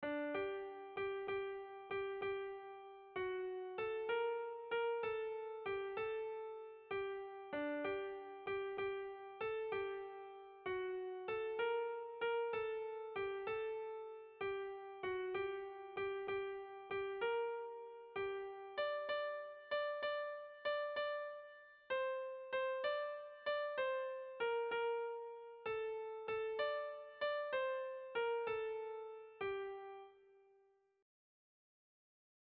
Gabonetakoa
AABB